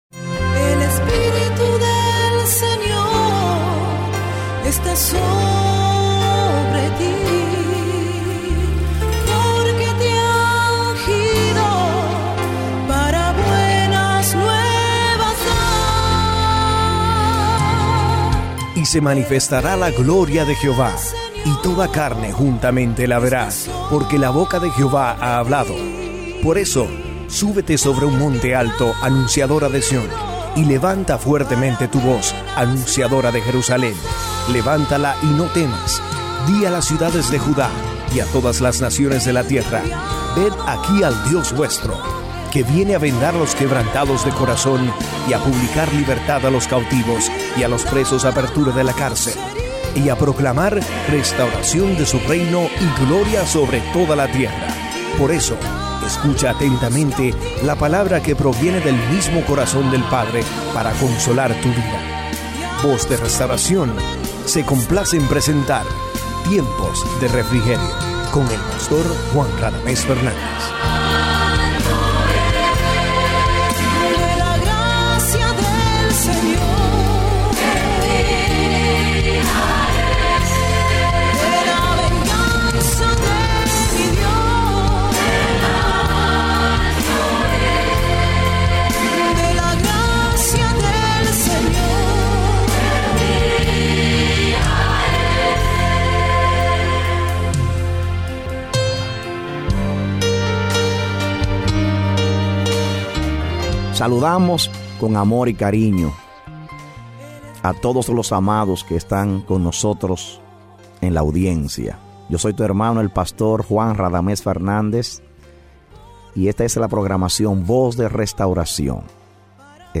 A mensaje from the serie "Programas Radiales."